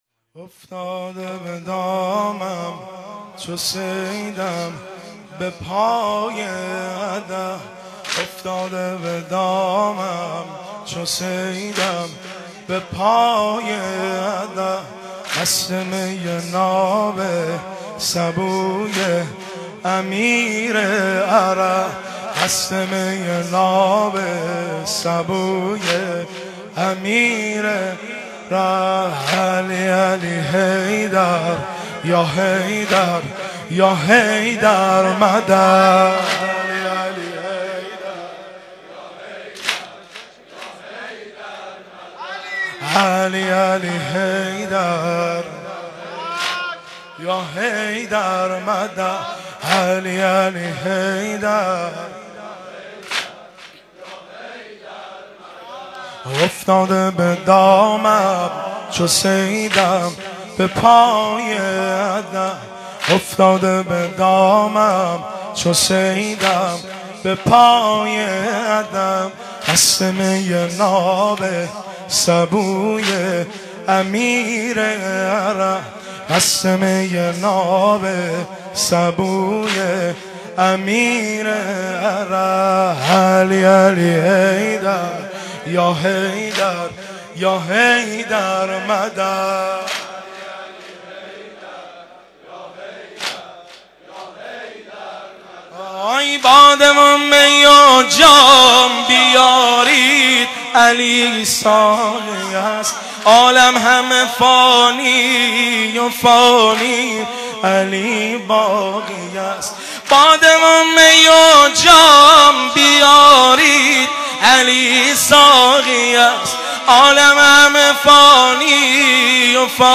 دانلود نوحه افتاده به دامم چو صیدم بپای ادب